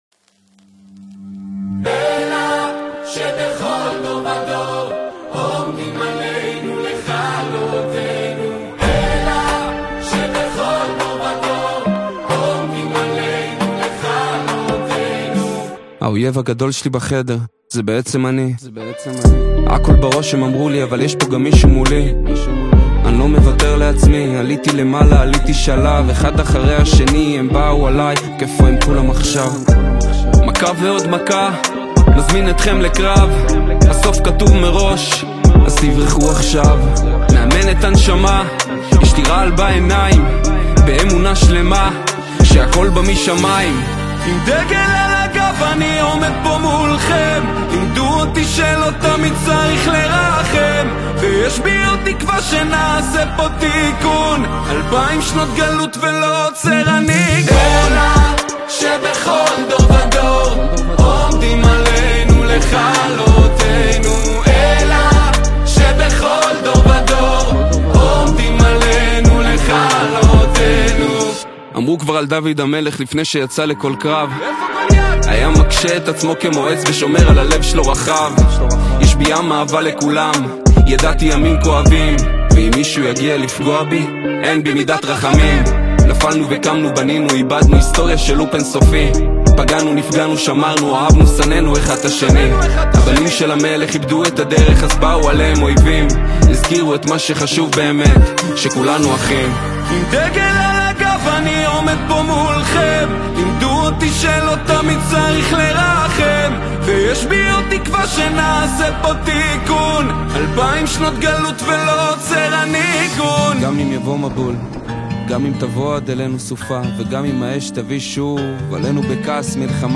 קולות רקע